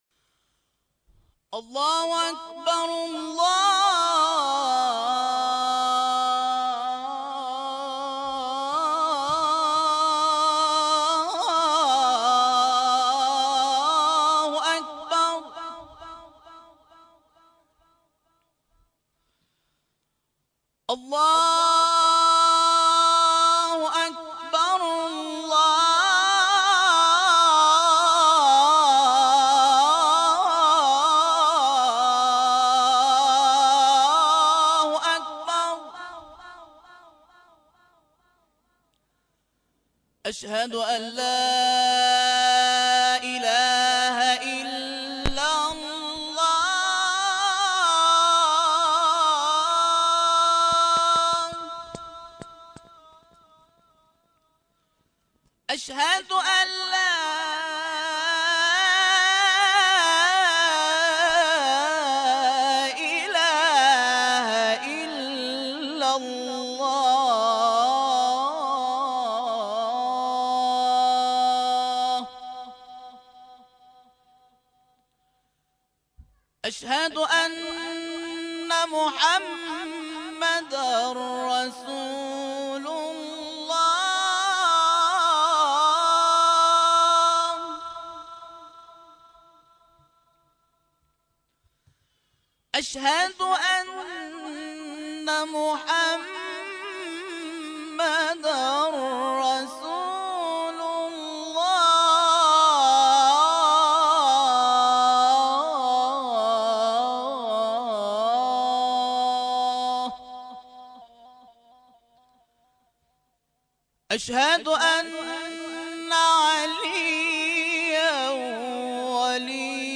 مجموعه کامل صوتي اذان
اذان